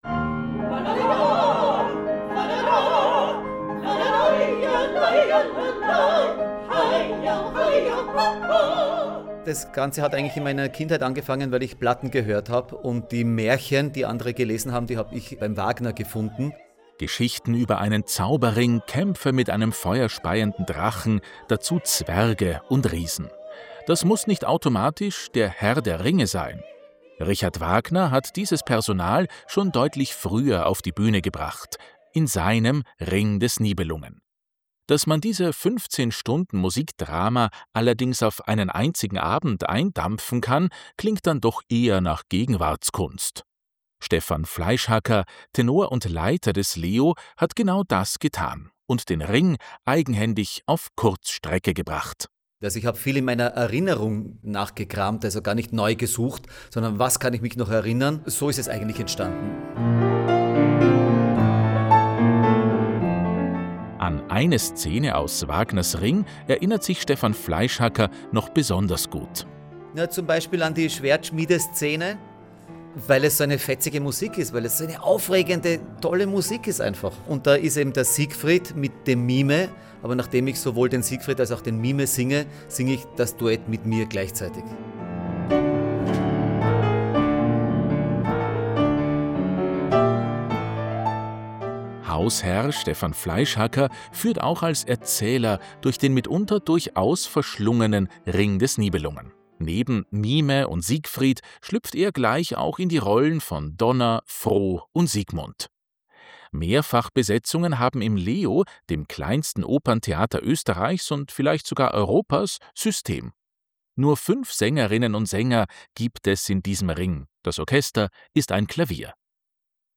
Probenbericht